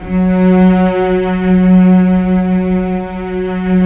Index of /90_sSampleCDs/Roland L-CD702/VOL-1/STR_Symphonic/STR_Symph.+attak